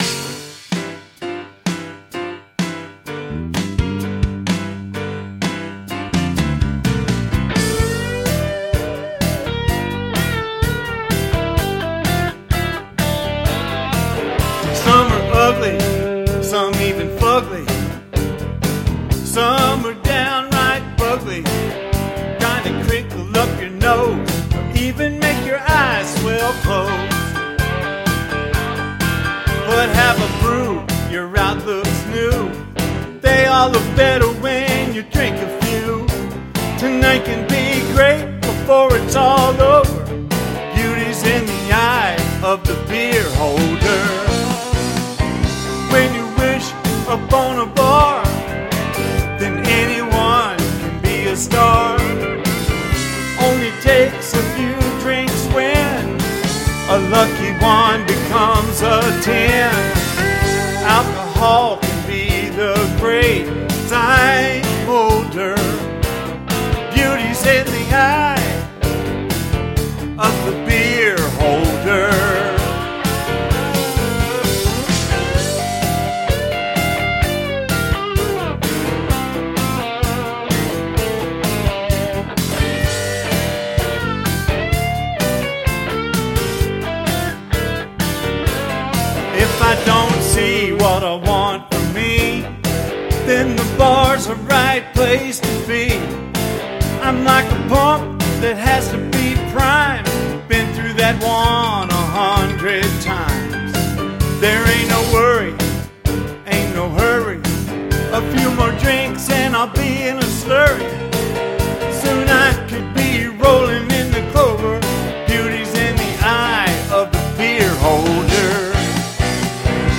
Complete Song:
Complete Demo Song, with lyrics and music: